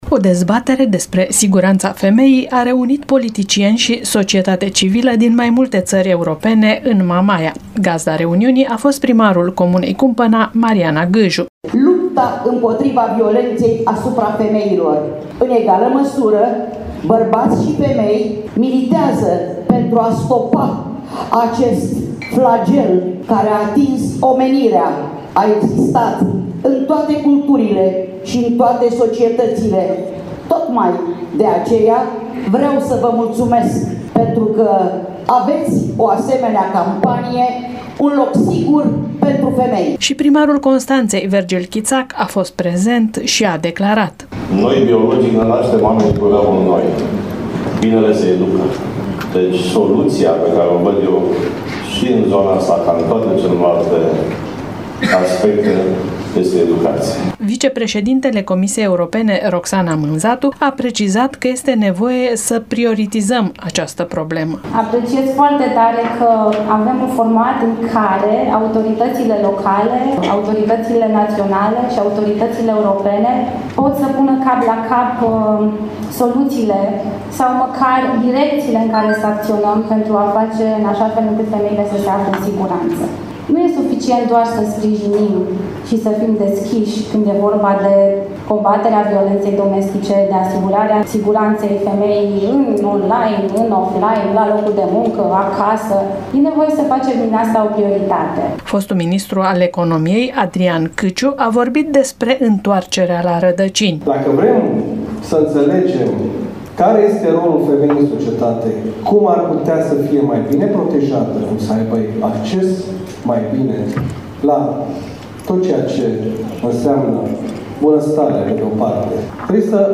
O vizită de studiu și dialog cu cetățenii a avut loc astăzi în cadrul evenimentului organizat de Grupul Socialiștilor Europeni din Comitetul European al Regiunilor.